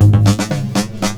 DRUMFILL05-L.wav